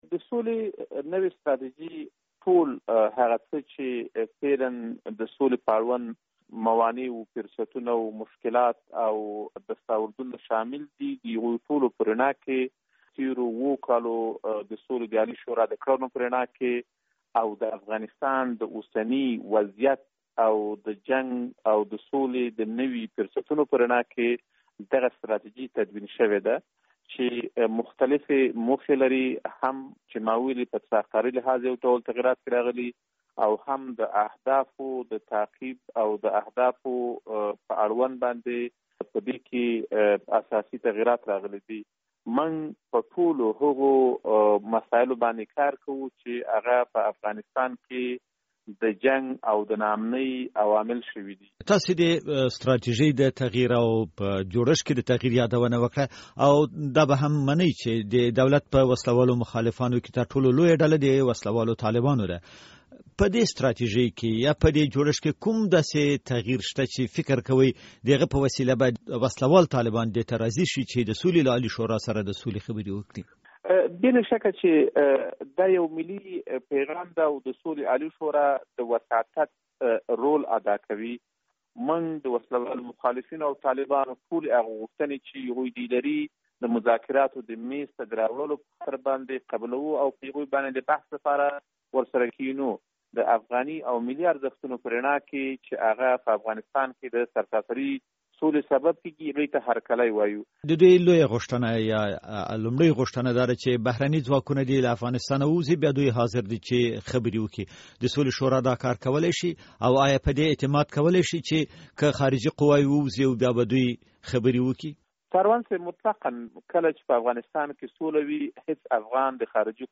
مرکه
له اکرم خپلواک سره مرکه